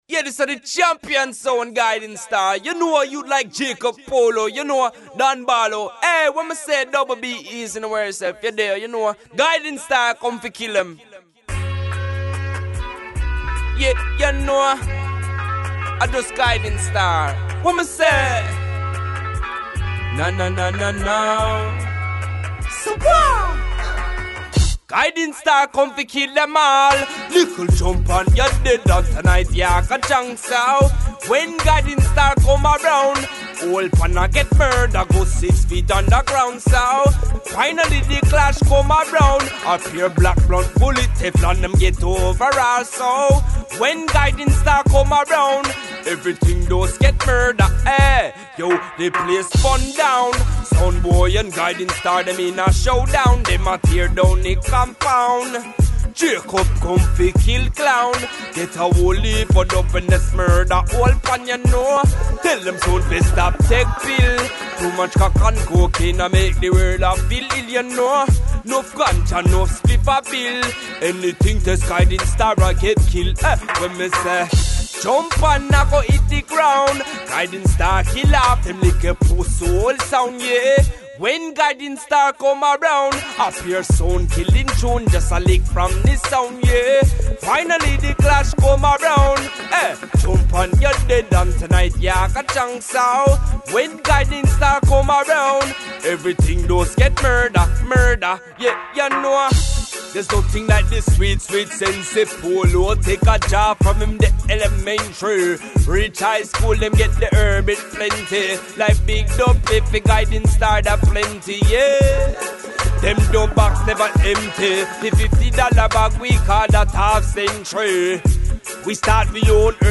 Raggae sound System music